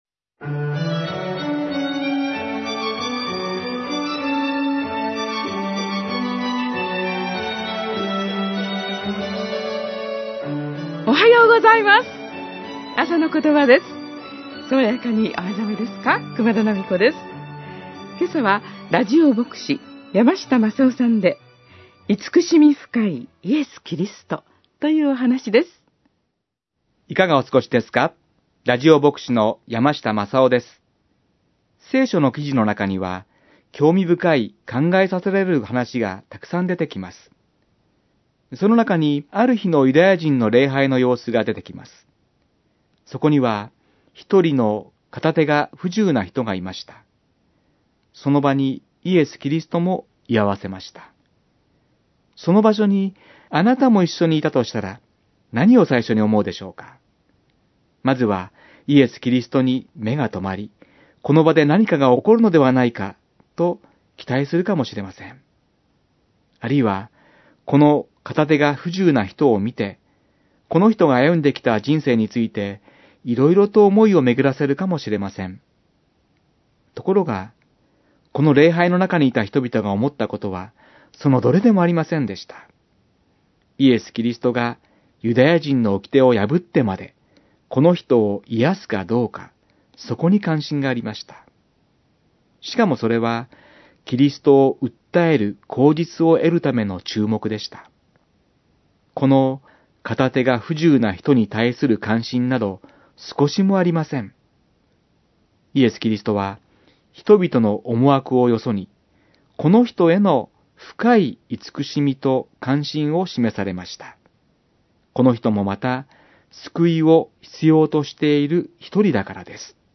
メッセージ： 慈しみ深いイエス・キリスト